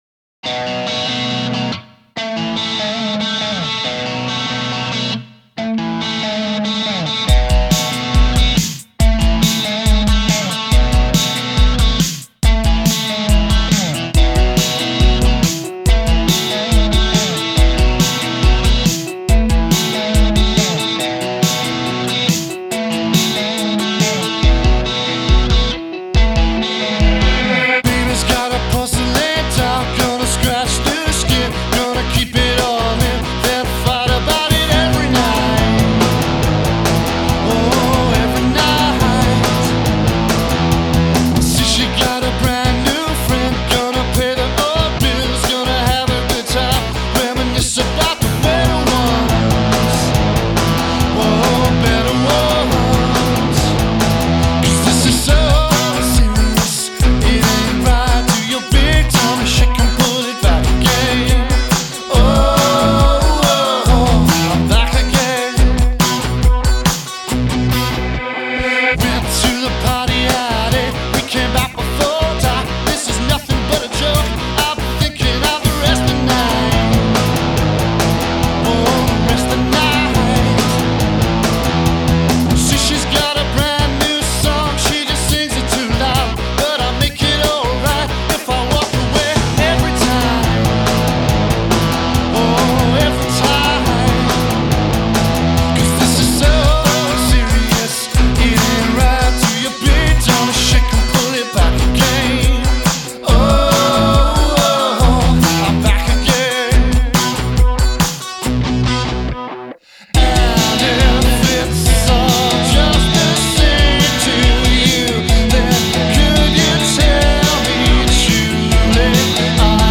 Микс под критику
Indie Pop/Rock